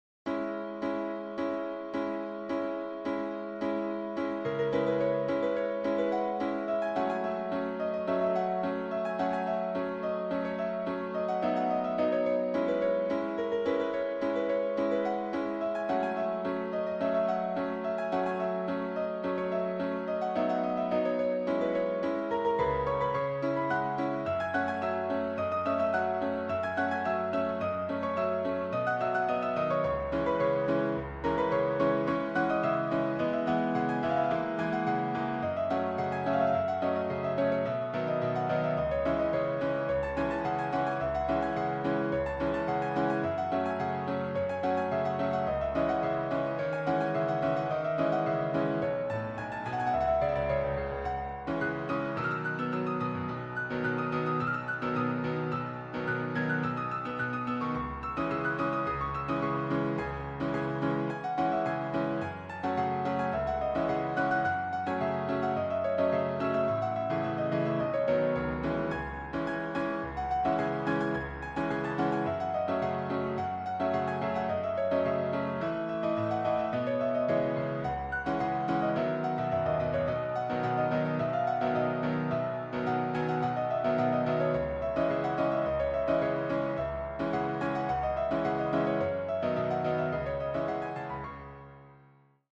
Müəllif: Qafqaz - Anadolu Musiqi / Rəqsi